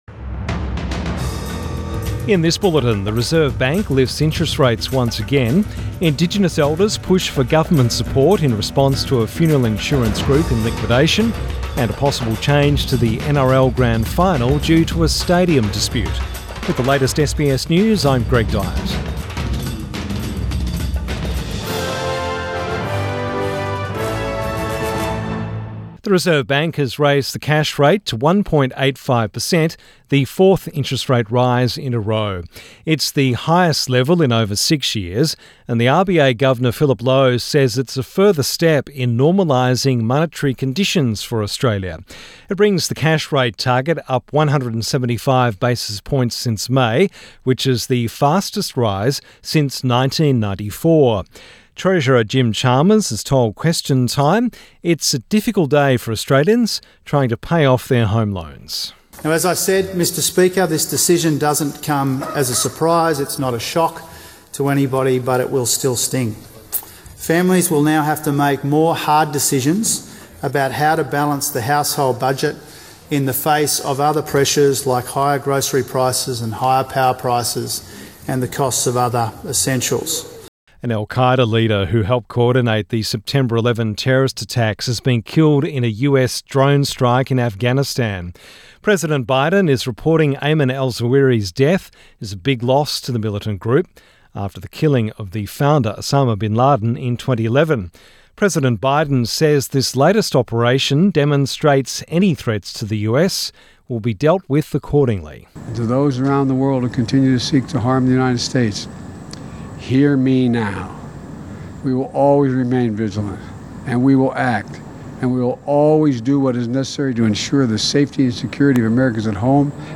PM bulletin 2 August 2022